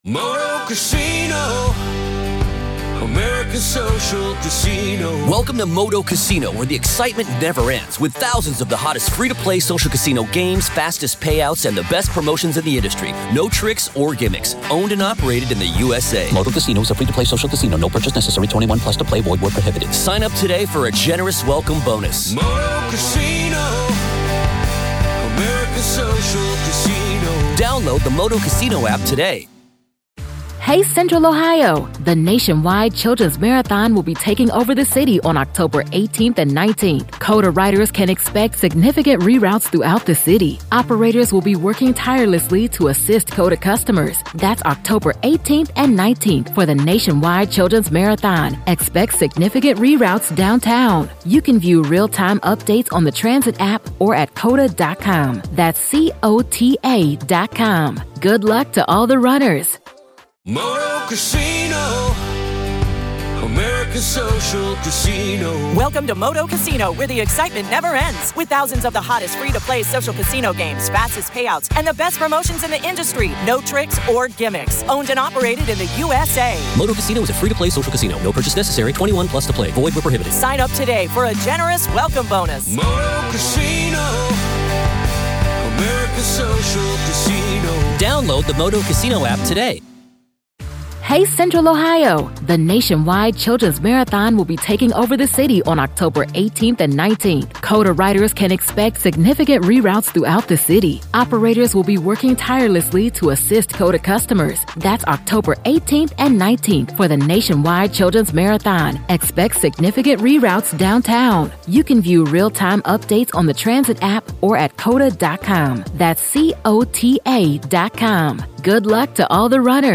In this interview, we explore: